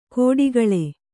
♪ kōḍigaḷe